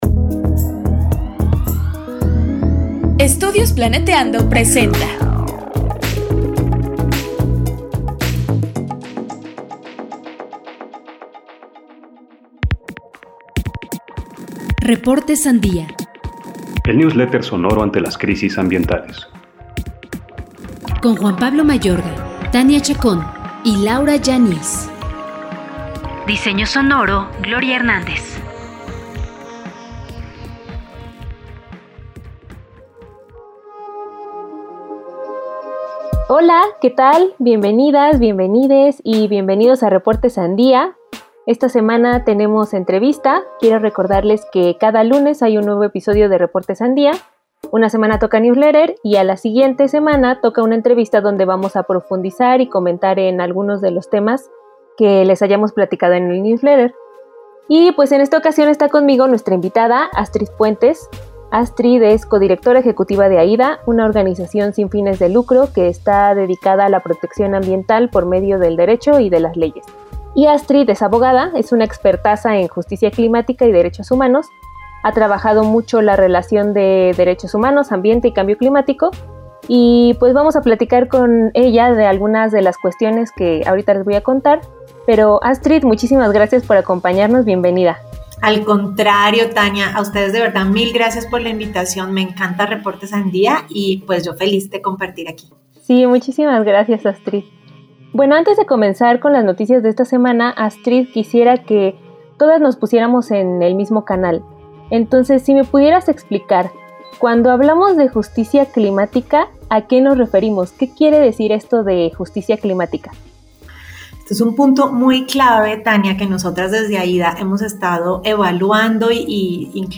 Entrevistamos
RS-ENTREVISTA-2-mezcla.mp3